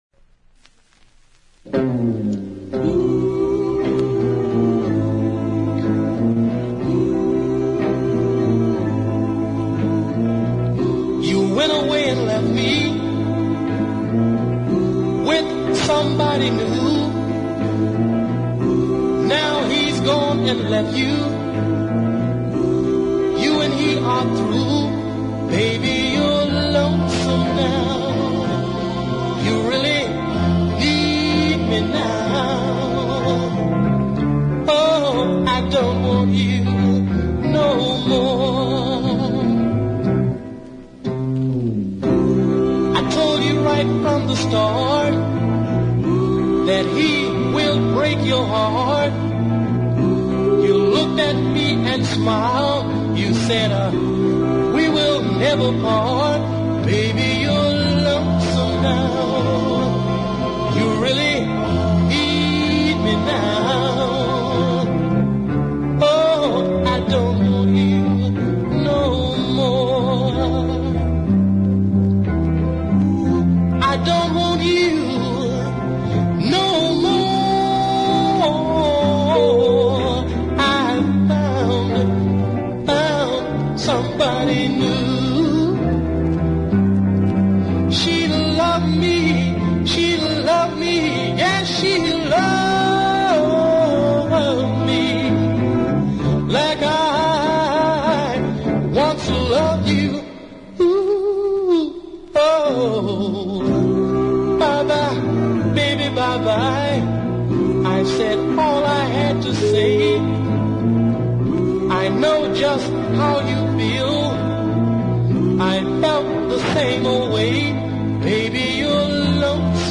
a delicious blend of soul, blues and doo-wop